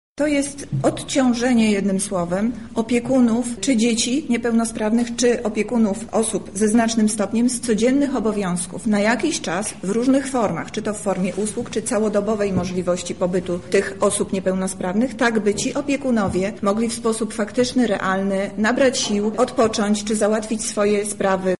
O tym, na czym będzie polegać pomoc i komu ma ona służyć, mówi Zastępca Prezydenta Miasta Lublin Monika Lipińska: